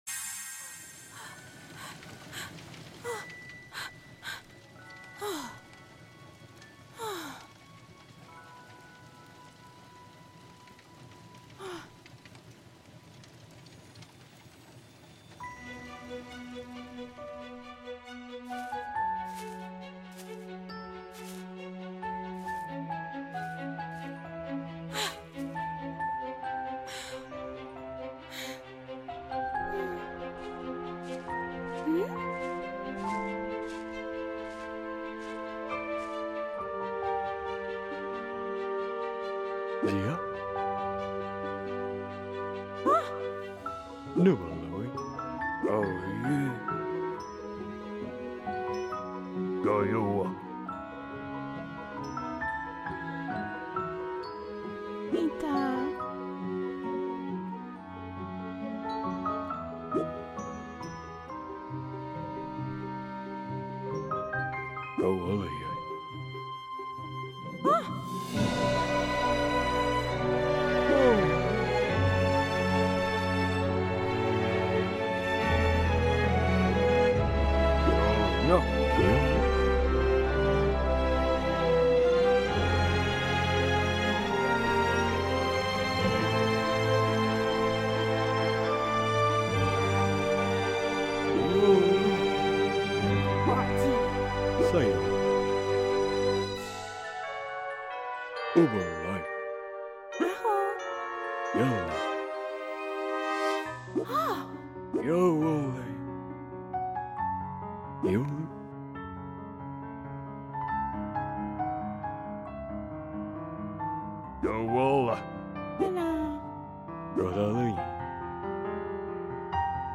here's the intro cutscene